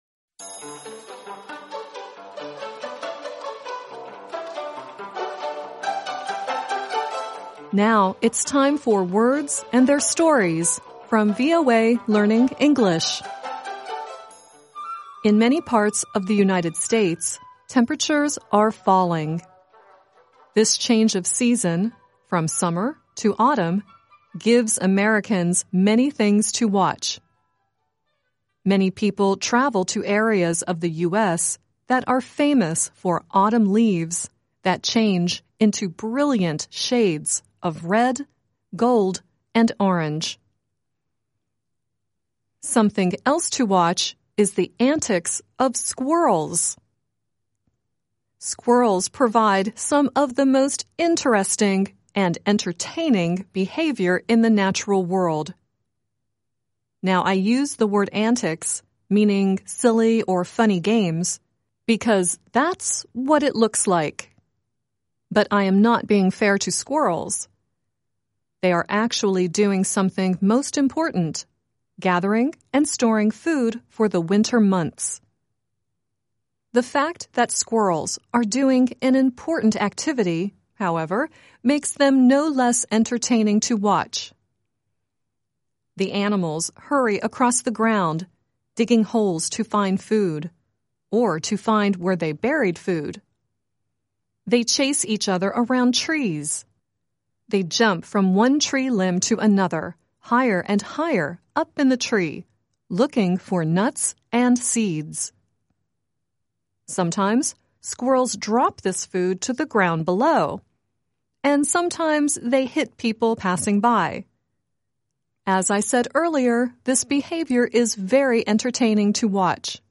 The song at the end is Ray Stevens singing "Mississippi Squirrel Revival."